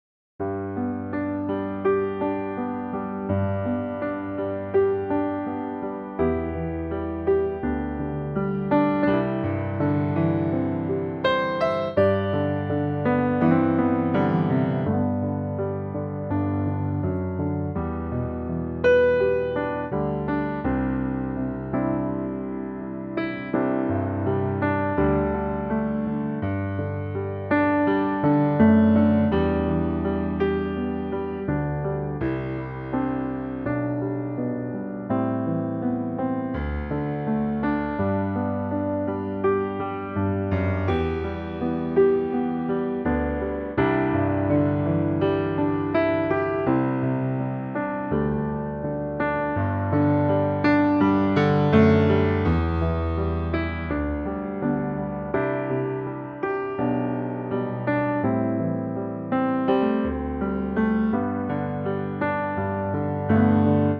Unique Backing Tracks
key - G - vocal range - G to B
Lovely piano only arrangement